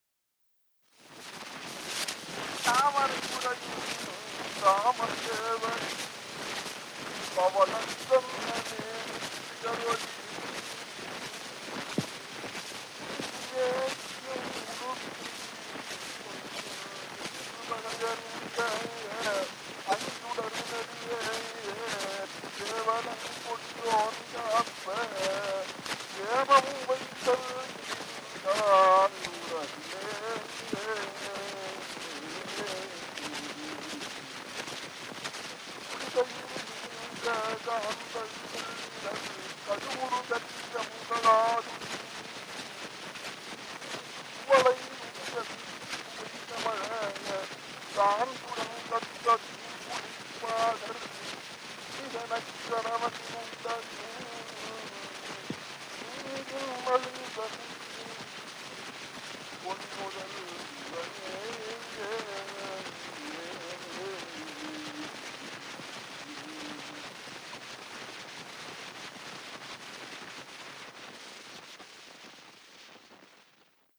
chiefly recitations of classical Tamil poetry
Madras/Chennai
Historical sound recordings